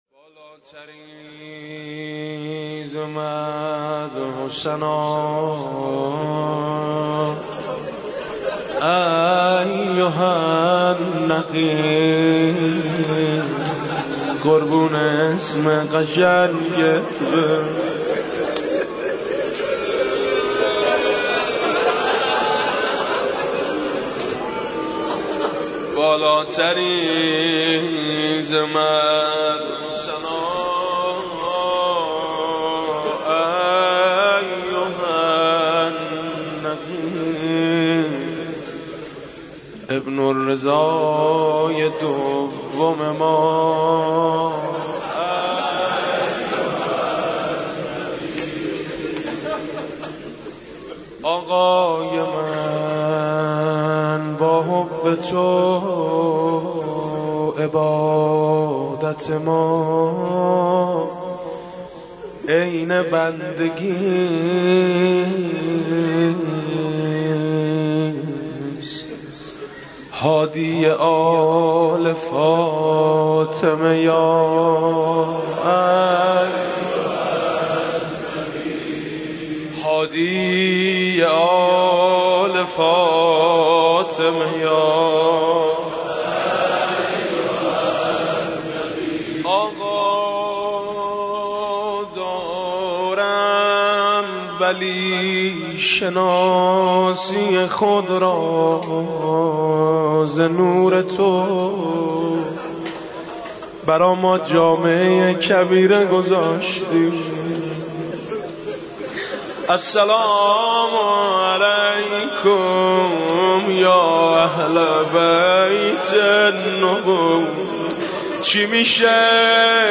مداحی حاج میثم مطیعی به مناسبت شهادت امام هادی(ع)